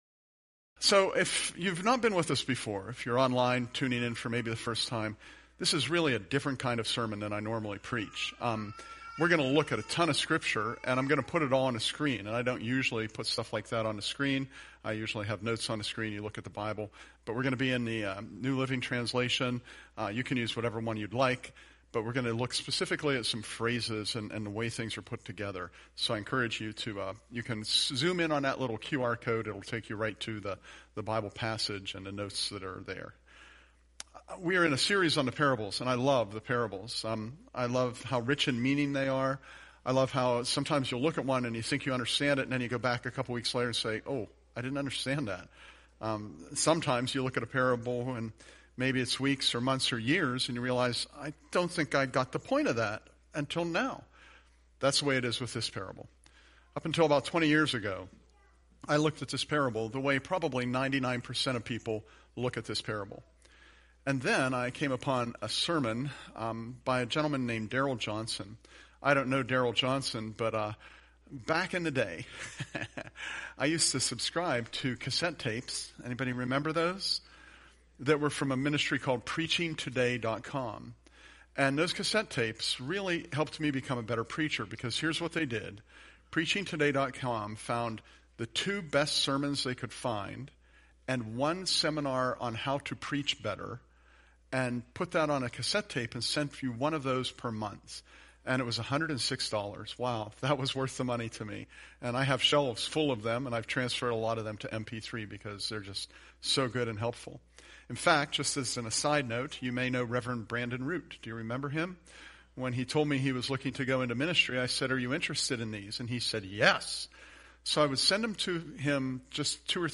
Presented at Curwensville Alliance on 2/23/25